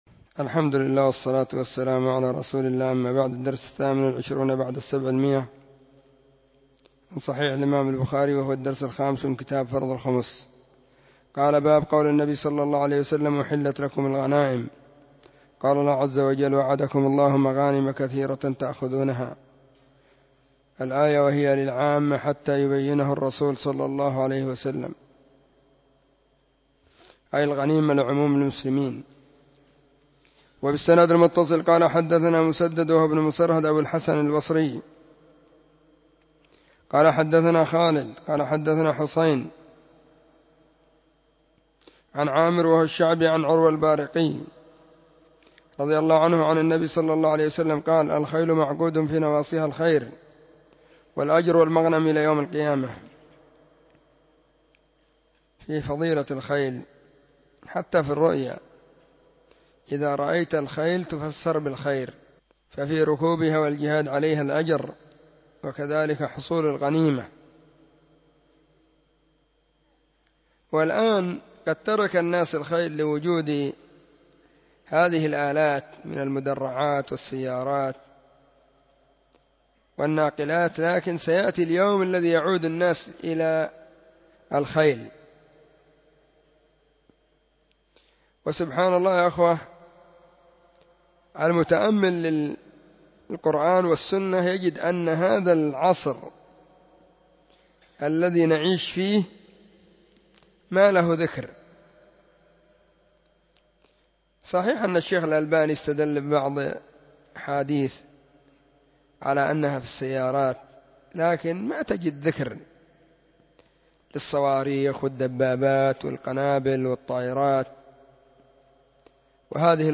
🕐 [بين مغرب وعشاء – الدرس الثاني]
كتاب-فرض-الخمس-الدرس-5.mp3